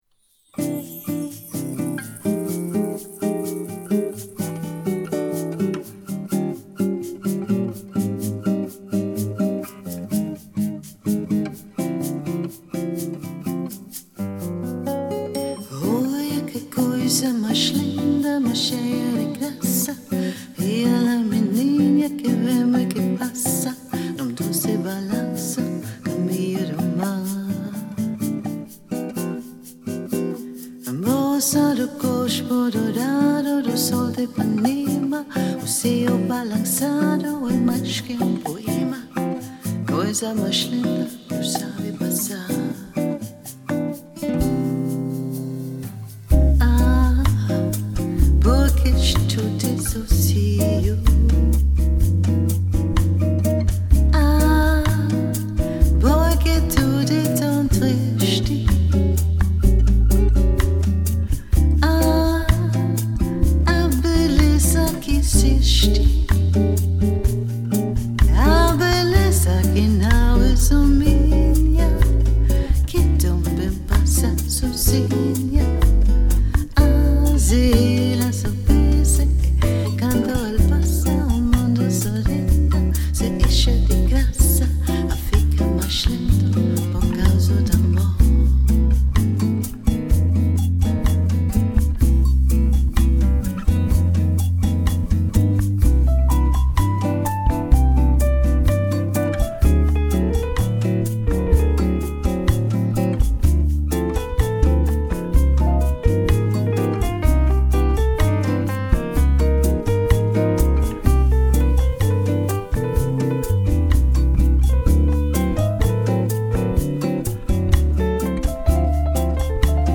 VIP Sangerinde Lounge Diva stemning Solo - Duo - Trio